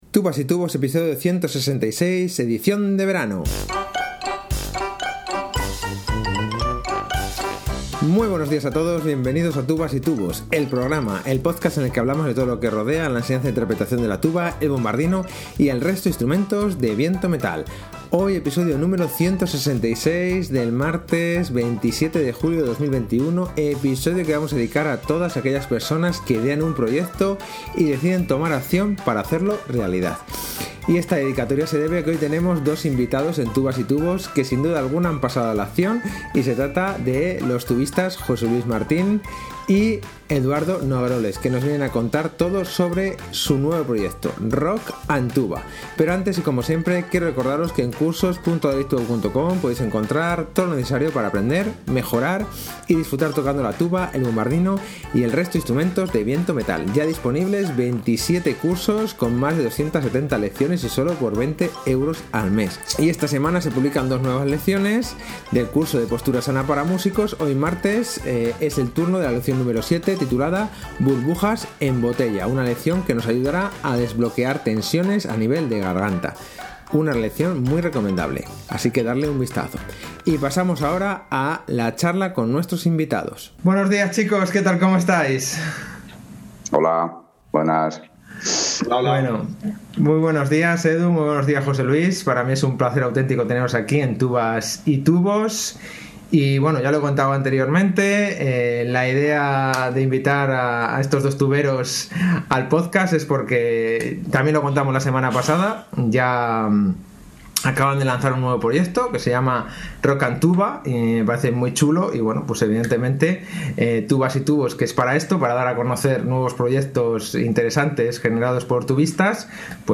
Esta dedicatoria se debe a que hoy tenemos dos invitados en Tubas y tubos que sin duda alguna han pasado a la acción